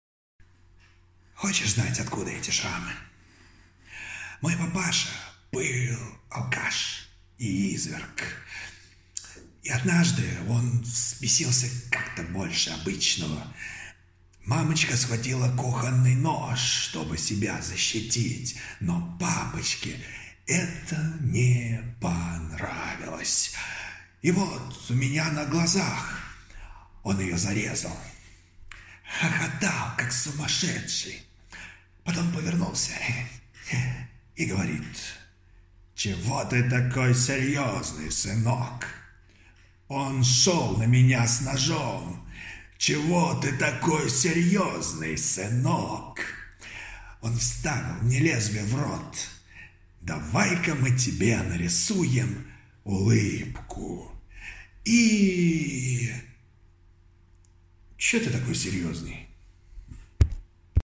Мужской
Баритон